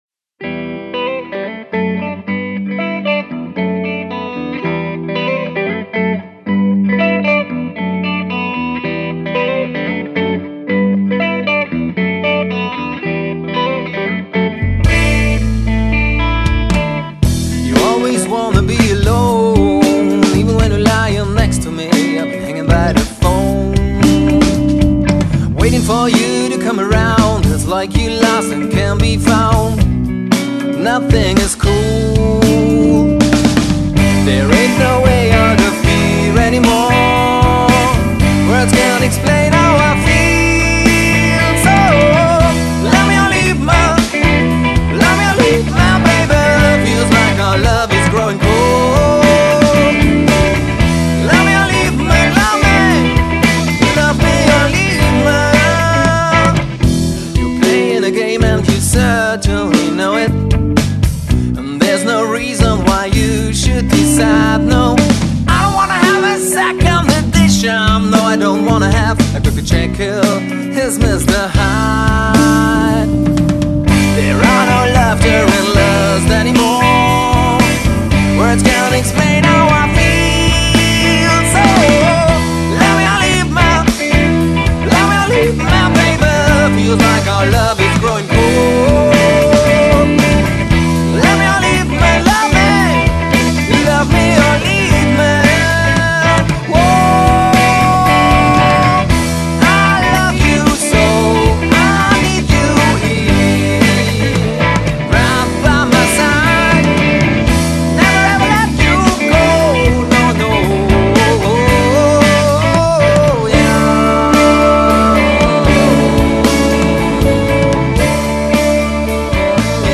Singer-Songwriter Pop Songs